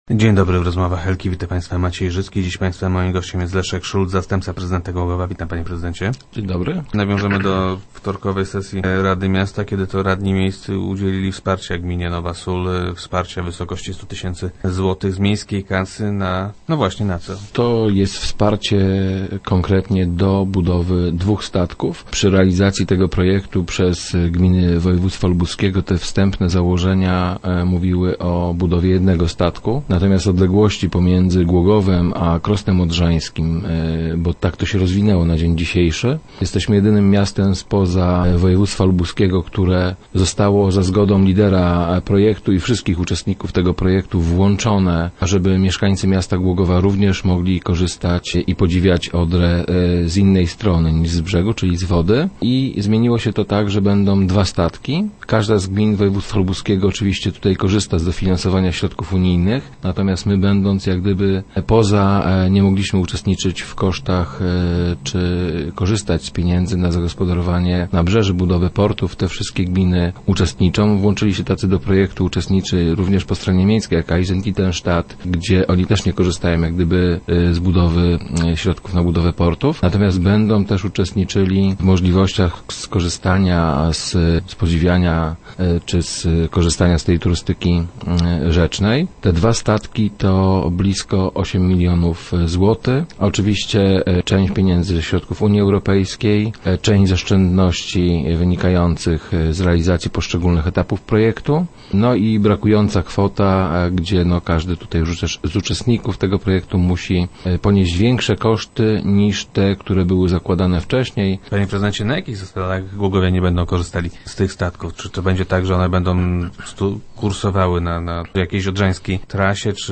- W przyszłości ten teren zostanie podniesiony, żeby można było korzystać z niego także wówczas, gdy poziom rzeki jest wysoki - informuje Leszek Szulc, zastępca prezydenta Głogowa, który był gościem Rozmów Elki.